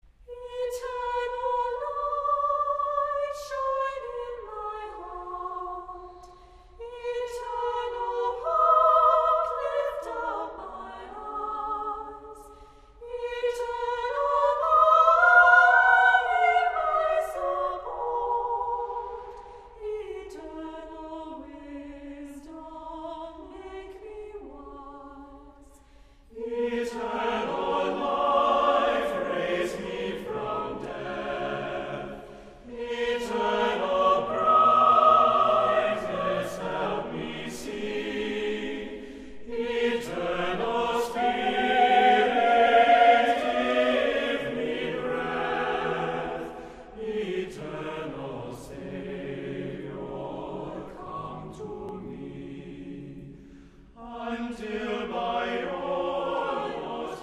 • Music Type: Choral
• Voicing: SATB with divisi
*Moving, simple setting of this classic prayer
*a cappella with some divisi